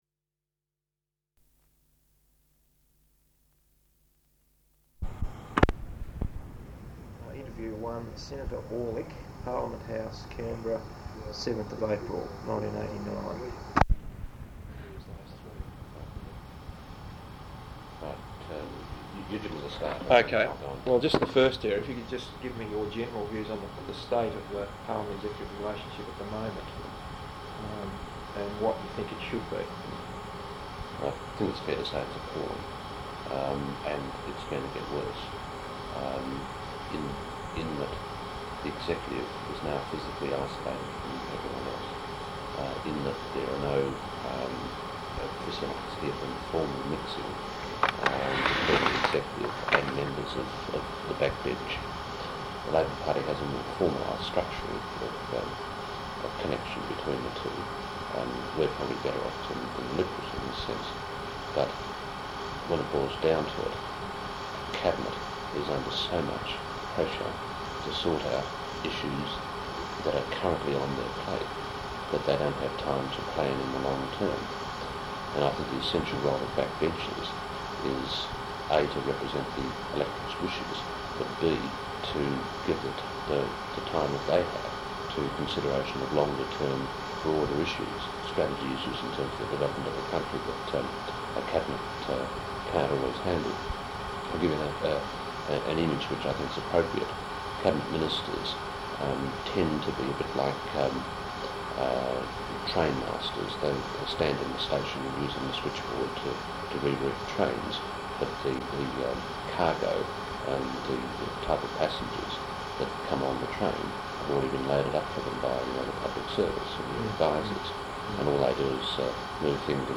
Interview with Senator Terry Aulich, Labor Senator for Tasmania, Parliament House, Canberra 7th April 1989.